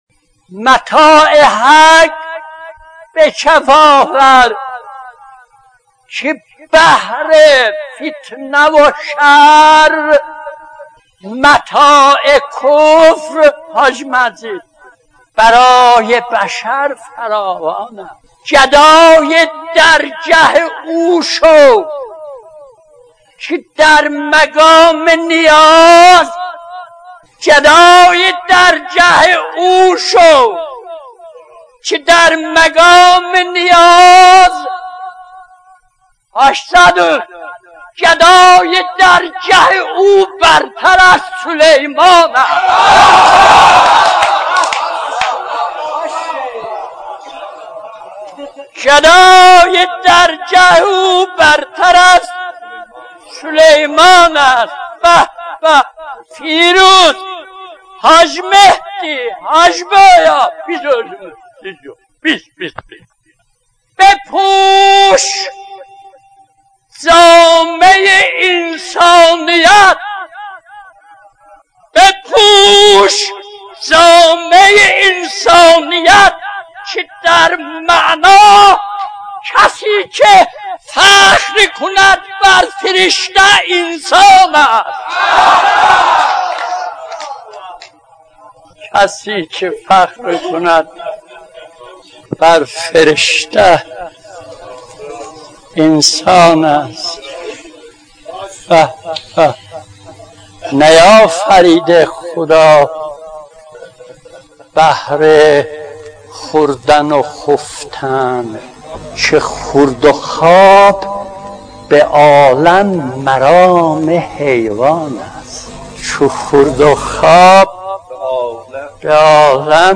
مناجات9.mp3